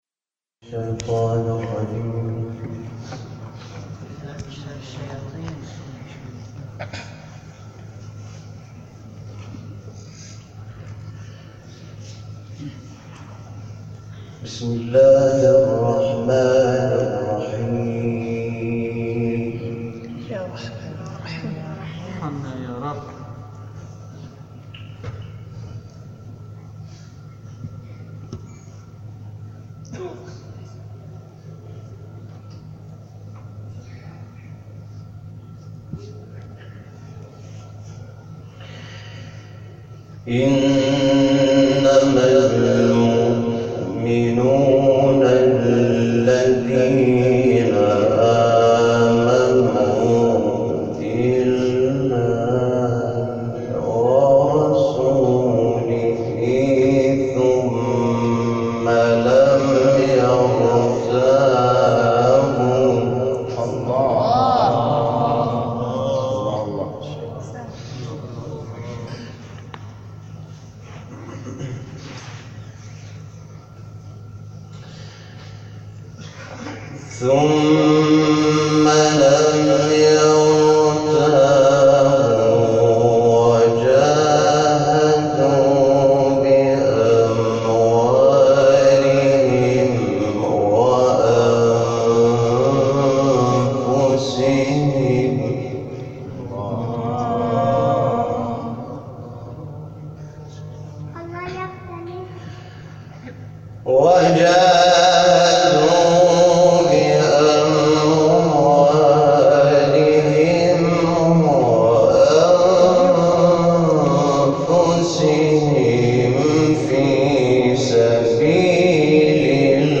تلاوت «شاکرنژاد» در حسینیه بنی‌الزهرا(س) + تصاویر
گروه شبکه اجتماعی: تلاوت شب اول و دوم حامد شاکرنژاد در حسینیه بنی الزهراء(س) را می‌شنوید.